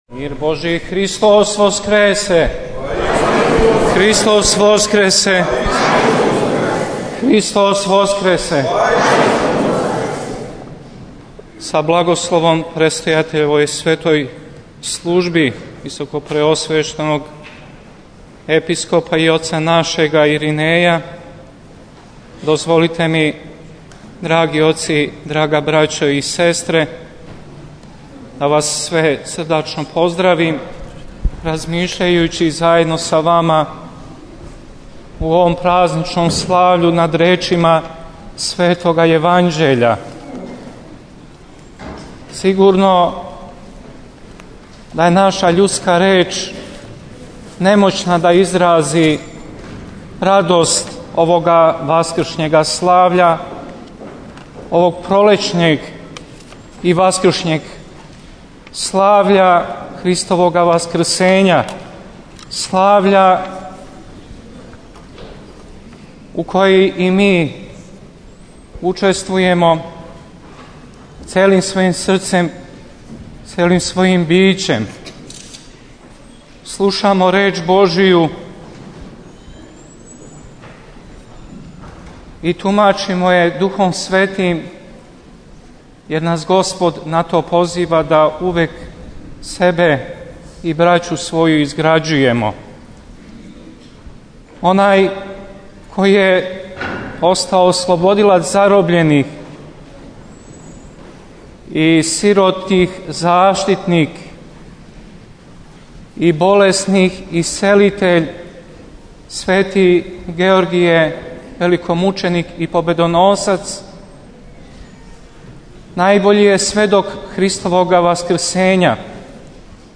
Сомбор - На празник светога, славнога и свехвалнога великомученика, победоносца и чудотворца Георгија, патрона града Сомбора, 6. маја 2011. године, служена је света архијерејска Литургија у Светођурђевској цркви.
• Беседа Епископа диоклијског Јована: